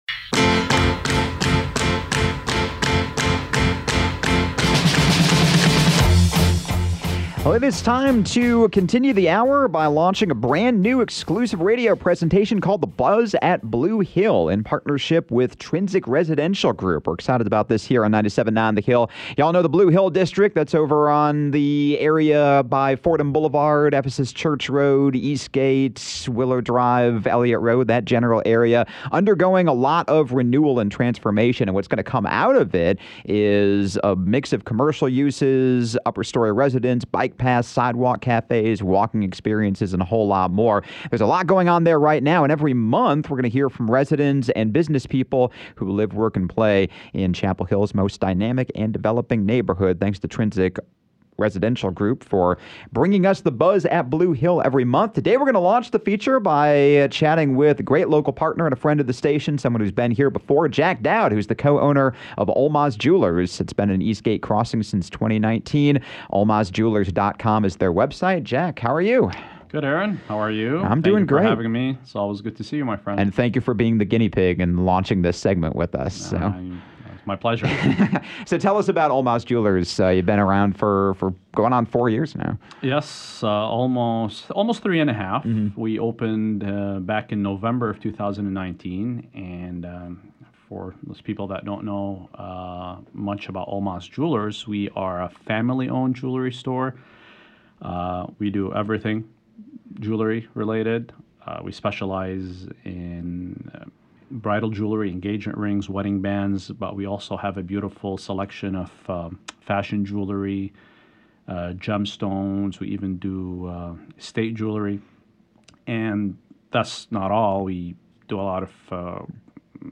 “The Buzz at Blue Hill” is an exclusive radio presentation in partnership with Trinsic Residential Group on 97.9 The Hill.